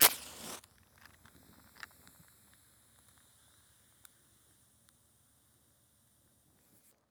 Matches 01.wav